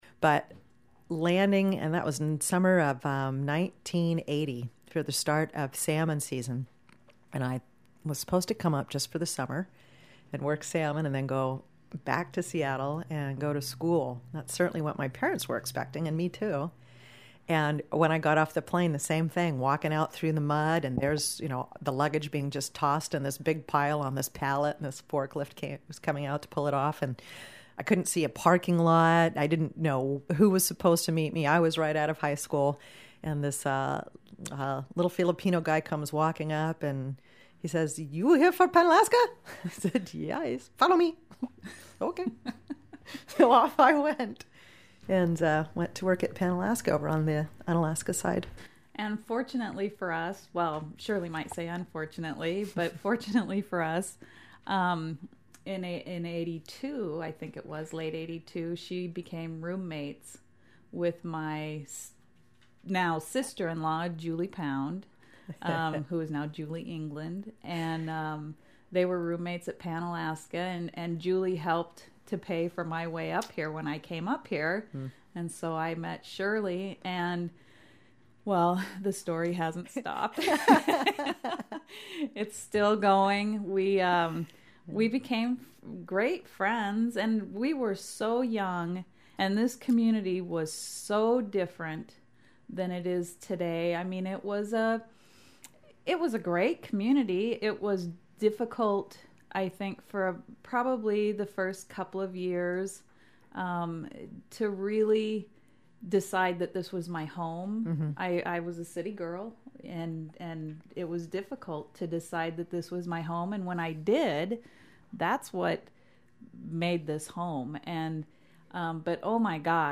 two old friends